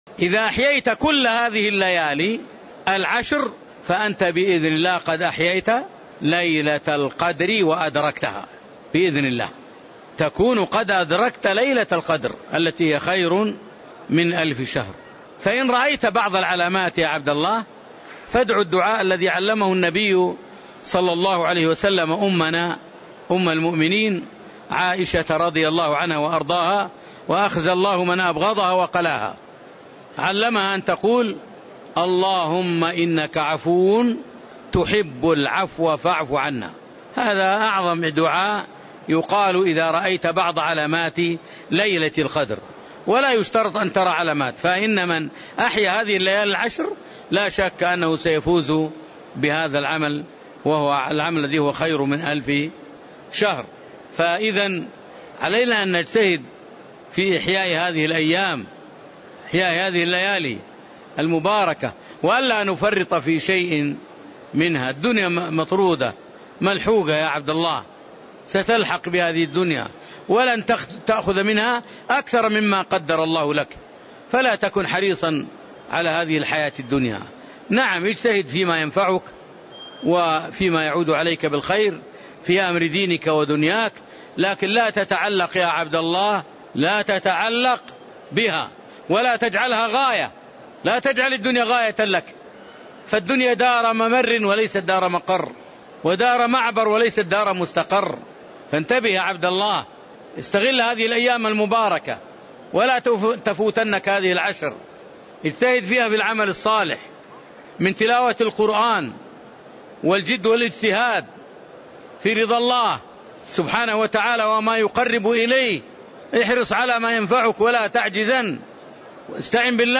موعظة إحياء العشر الأواخر من رمضان